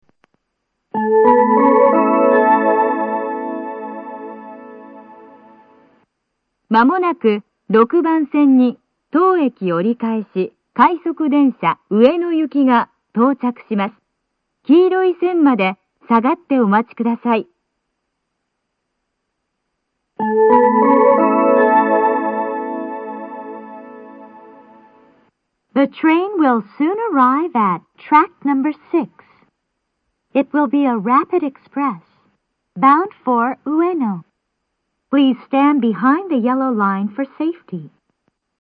この駅は禁煙放送が流れていて、それが被りやすいです。
６番線接近放送 当駅折り返し快速上野行の放送です。